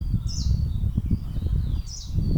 Zaļais ķauķītis, Phylloscopus trochiloides
Ziņotāja saglabāts vietas nosaukumsKolka
Zaļais ķauķītis perfekti imitē paceplīti, putns novērots vizuāli dziedam abās dziesmās